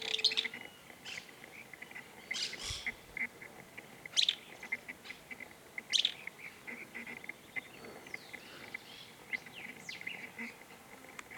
Barulhento (Euscarthmus meloryphus)
Nome em Inglês: Fulvous-crowned Scrub Tyrant
Localidade ou área protegida: Reserva Privada Punta del Agua
Condição: Selvagem
Certeza: Gravado Vocal